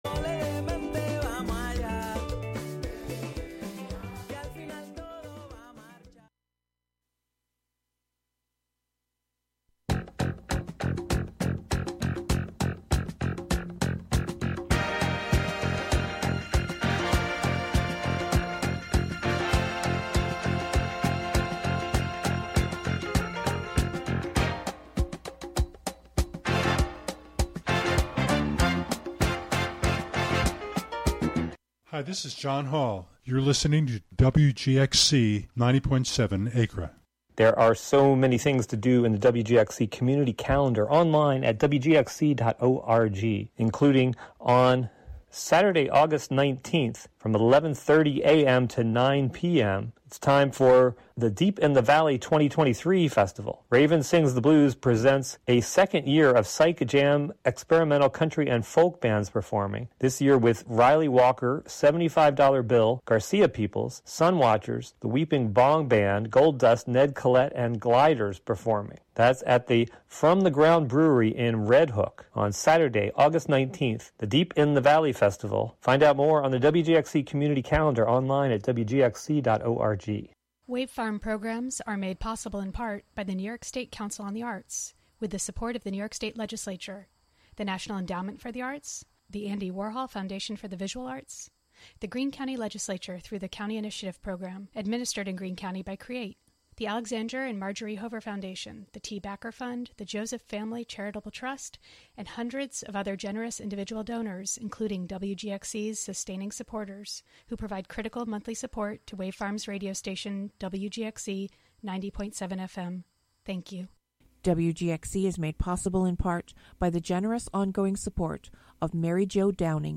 instrumental band